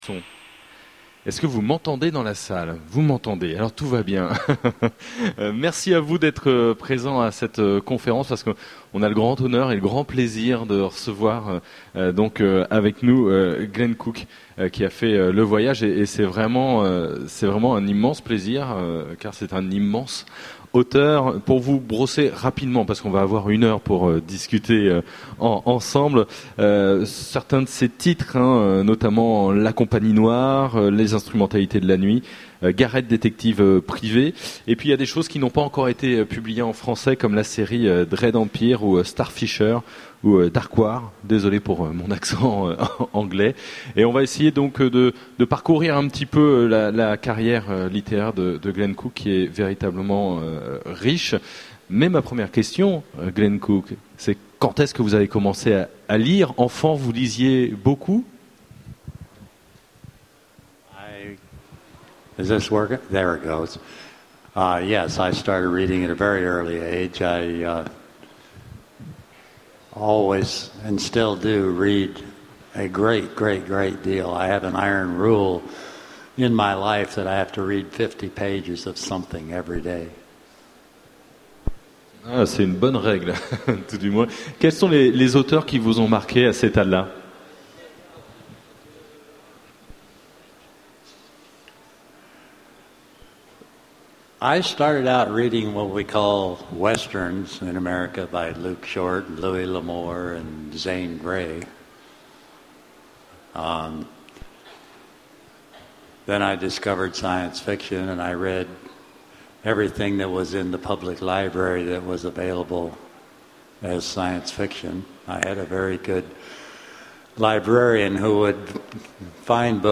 Utopiales 2011 : Conférence rencontre avec Glen Cook (VO)